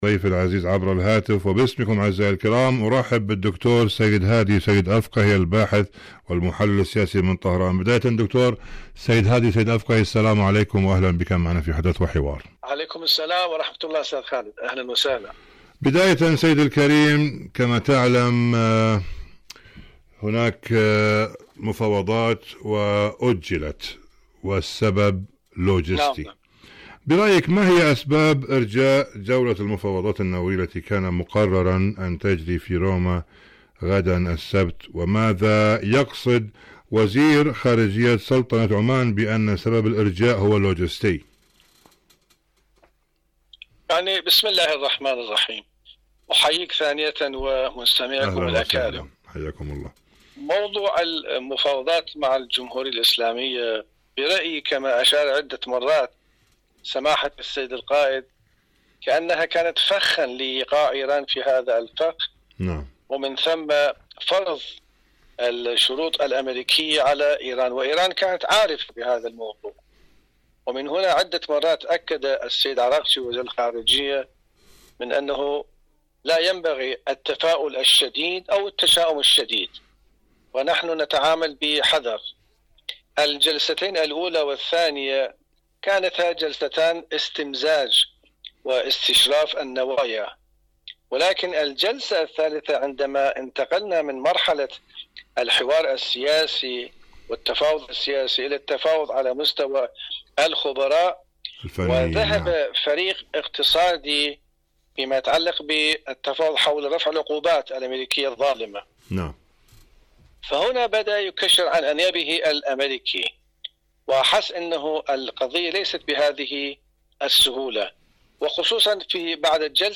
مقابلات برامج إذاعة طهران العربية برنامج حدث وحوار مقابلات إذاعية لماذا أرجئت الجولة الرابعة المباحثات النووية في روما؟